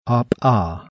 CONSONANTI (NON POLMONARI)